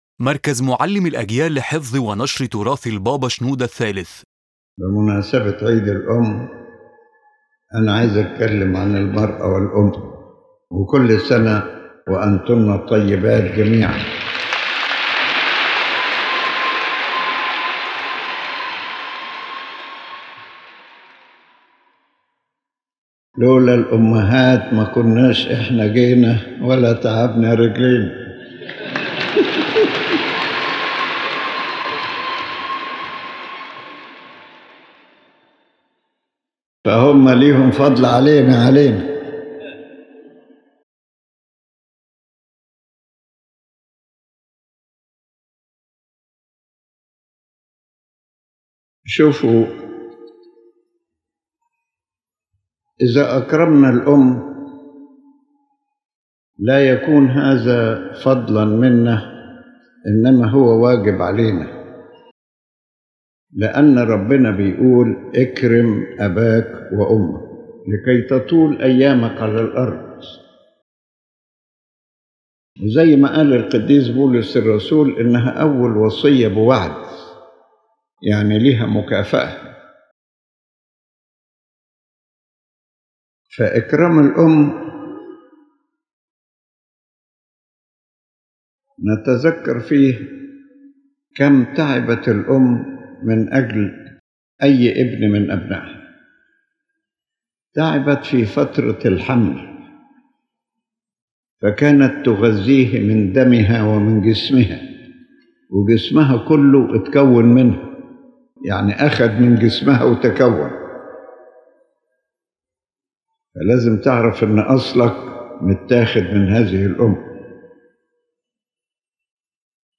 On the occasion of Mother’s Day, His Holiness Pope Shenouda III speaks about the dignity of women and the greatness of motherhood in human life and in the Church.